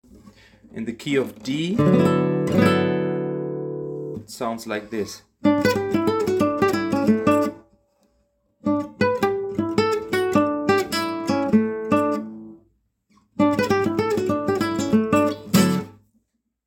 Major Ending Lick.mp3
I agree with you that this sounds like some sort of IV I V I (or ii I V I), and the triads function as upper extensions of these chords.
major-ending-lick.mp3